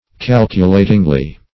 Meaning of calculatingly. calculatingly synonyms, pronunciation, spelling and more from Free Dictionary.